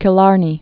(kĭ-lärnē)